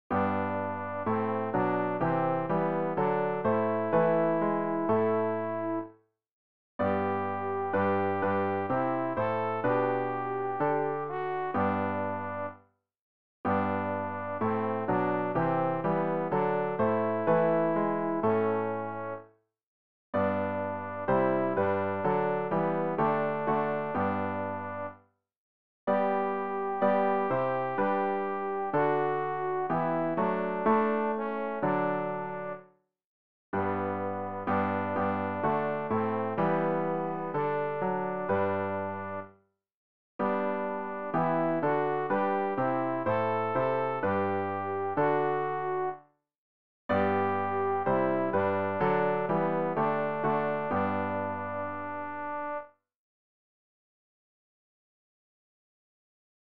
alt-rg-041-jauchzt-alle-voelker-preiset-alle.mp3